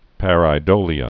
(părī-dōlē-ə)